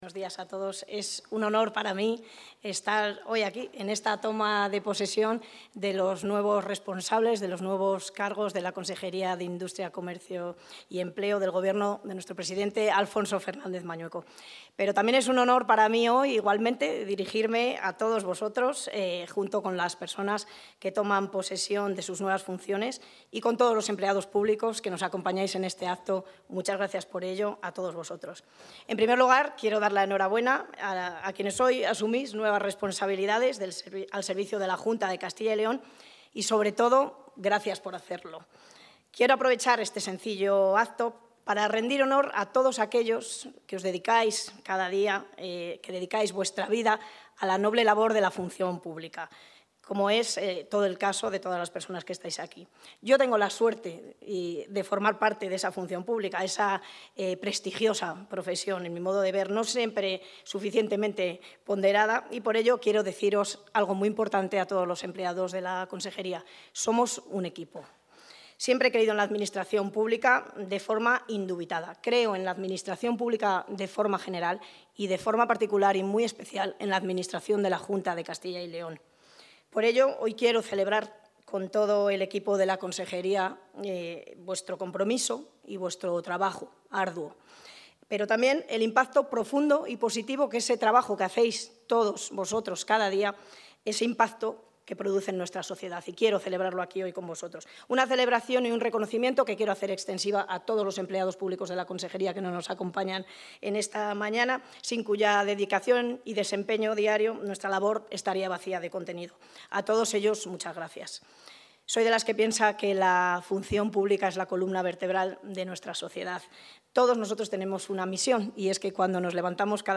Toma de posesión de los nuevos altos cargos de la Consejería de Industria, Comercio y Empleo
Intervención de la consejera de Industria, Comercio y Empleo.